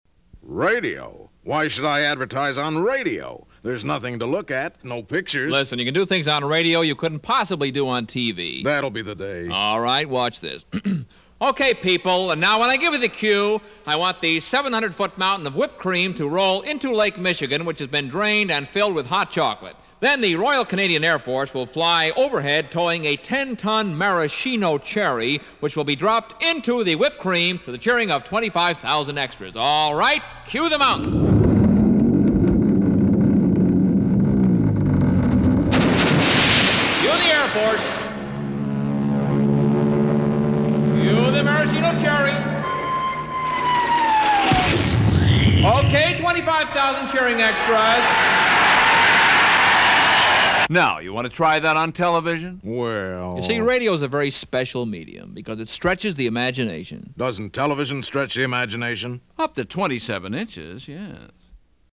Classic Theater of the Mind. It just struck me though, perhaps for the first time, that while the "fun" aspect of the spot is the sequence of sound effects at the end, it's the words used to describe what's coming that make all the difference.